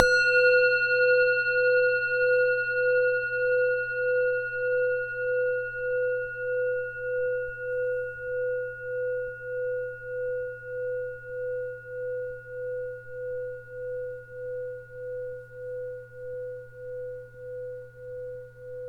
bell bowl dang ding dong dung gang ging sound effect free sound royalty free Voices